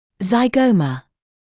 [zaɪ’gəumə][зай’гоумэ]скуловая кость